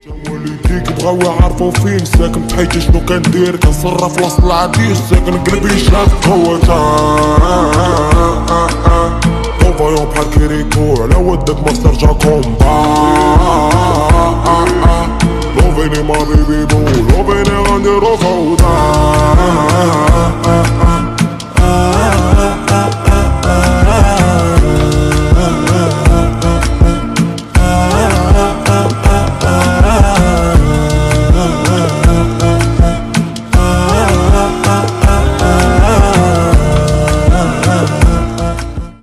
• Качество: 320 kbps, Stereo
Ремикс
клубные
громкие